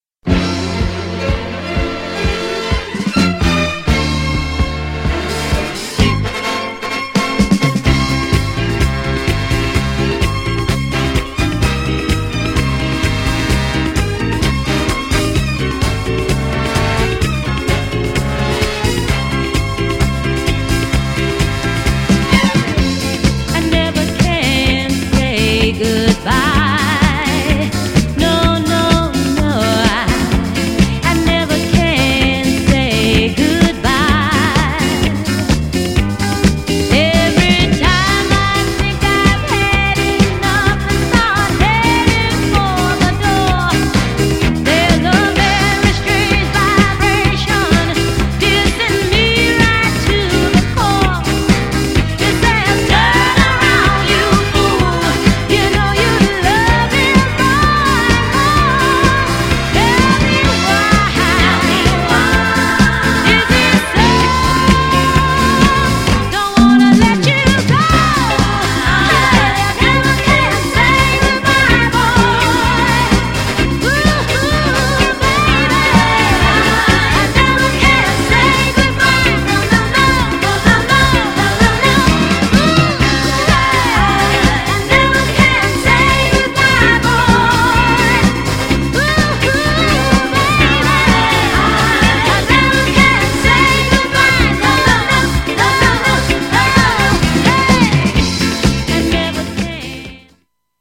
※浅い擦り傷多め、サーフィスノイズ出ます。
GENRE Dance Classic
BPM 116〜120BPM